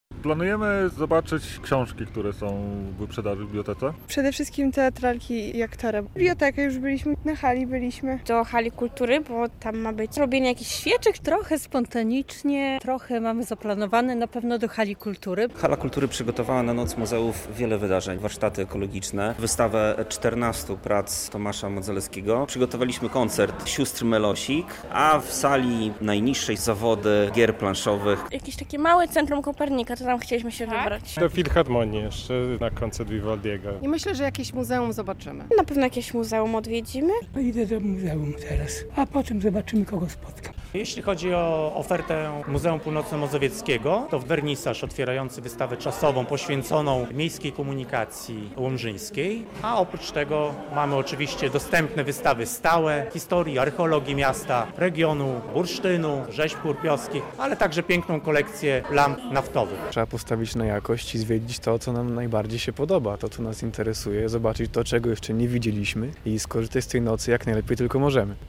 Noc muzeów w Łomży - relacja